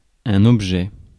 Ääntäminen
US : IPA : [eɪm]